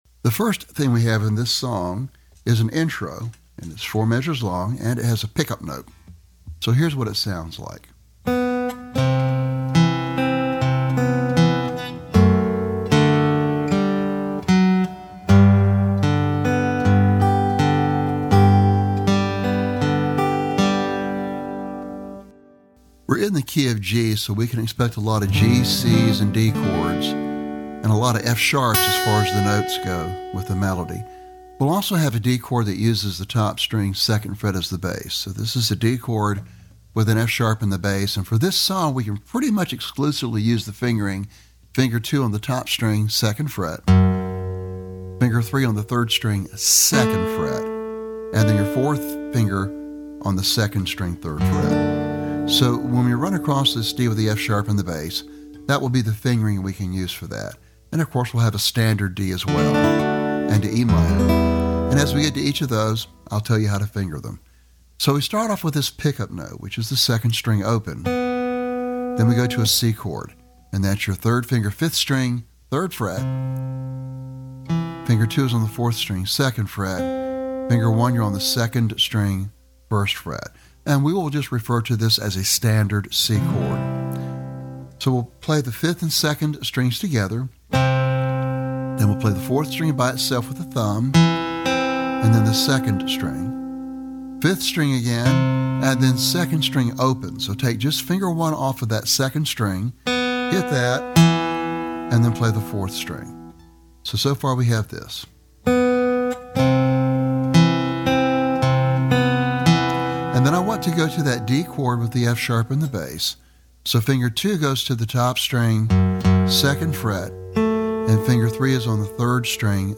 Lesson Sample Download